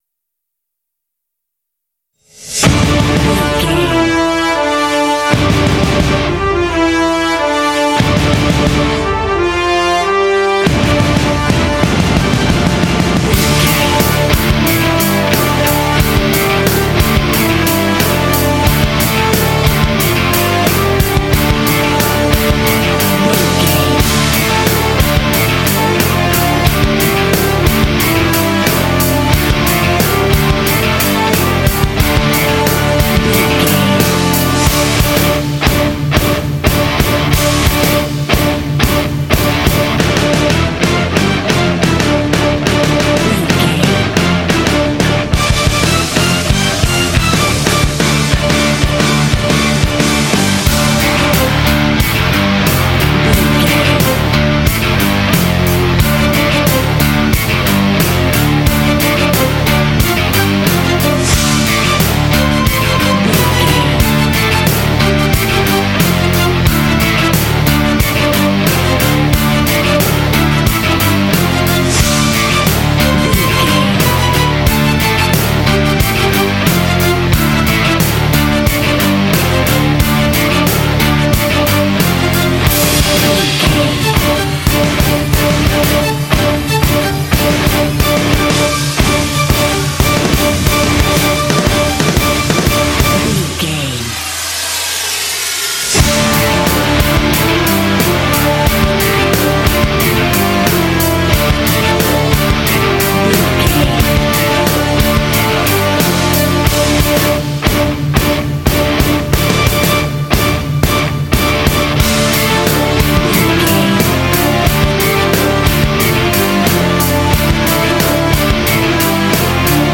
Aeolian/Minor
WHAT’S THE TEMPO OF THE CLIP?
intense
driving
aggressive
drums
electric guitar
bass guitar
strings
brass
symphonic rock